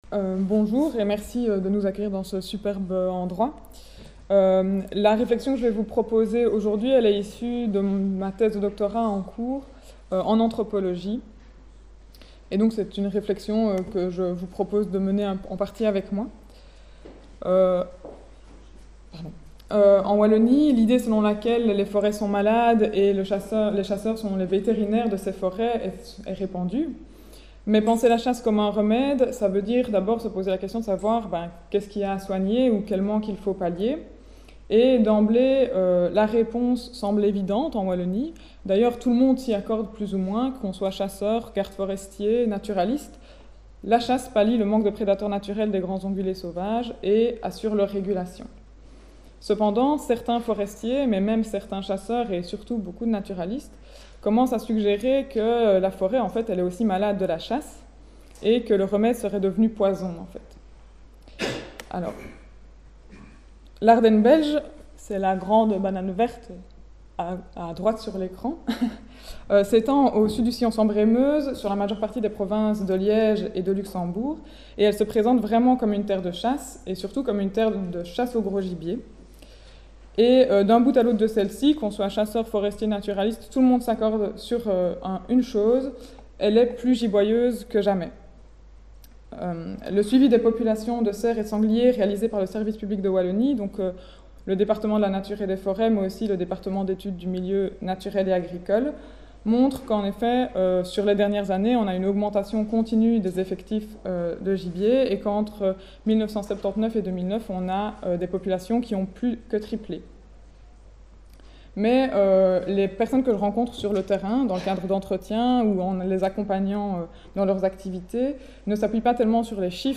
Ce colloque international, qui s'est tenu au sein du Domaine national de Chambord (26 et 27 mars 2019), s'inscrit dans le cadre du projet de recherche COSTAUD (Contribution des OnguléS au foncTionnement de l’écosystème et AUx services rendus à ChamborD, financé par la Région Centre-Val de Loire et porté par l'Irstea, 2016-2019).